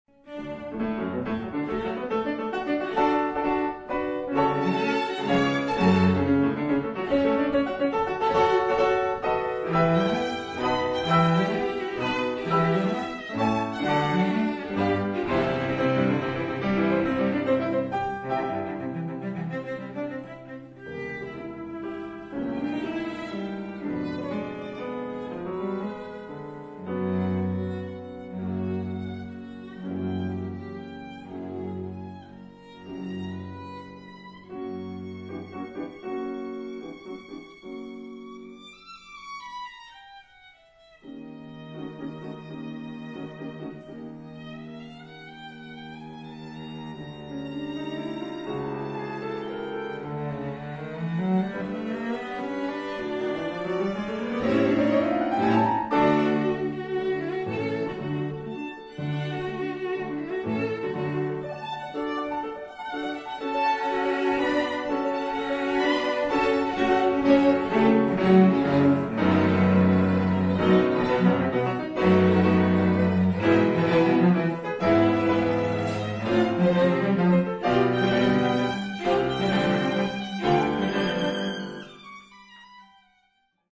Alto
Quatuor pour piano et trio à cordes
Violoncelle
En Ré Majeur